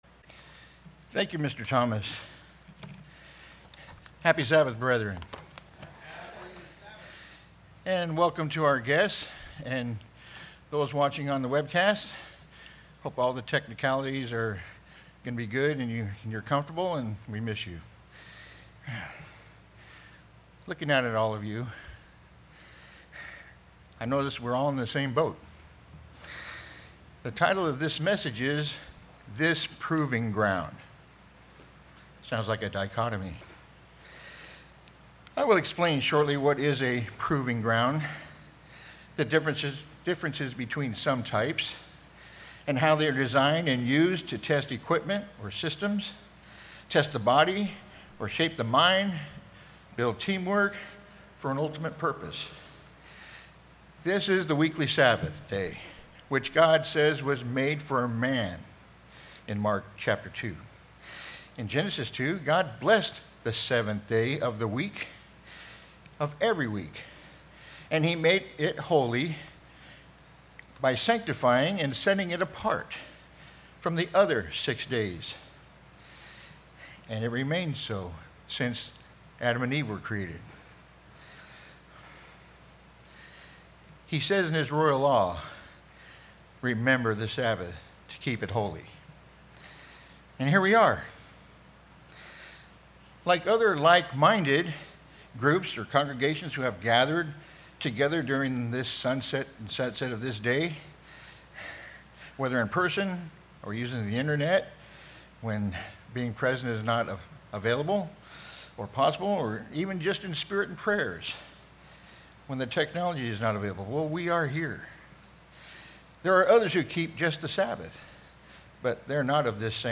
Sermons
Given in Orange County, CA